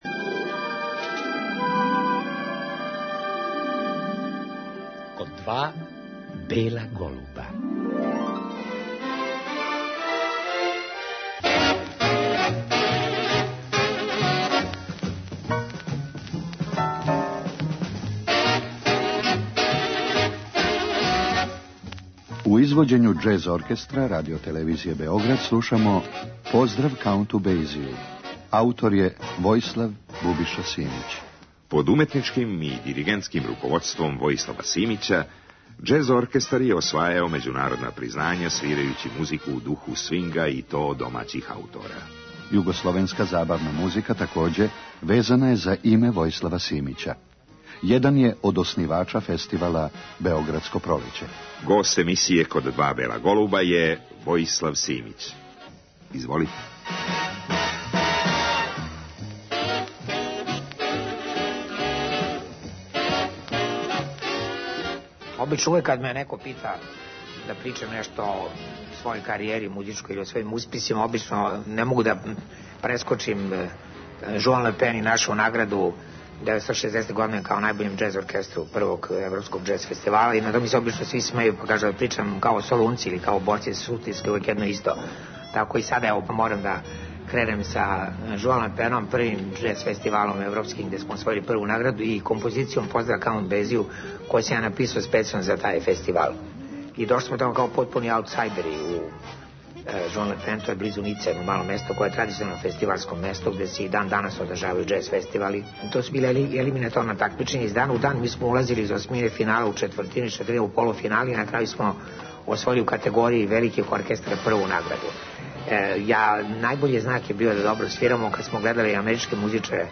Вечерас ћемо у емисији ''Код два бела голуба'' слушати сећања Војислава Бубише Симића, и чути делове концерта одржаног 9. априла 2001. године у Коларчевој задужбину поводом 60 година његовог уметничког рада.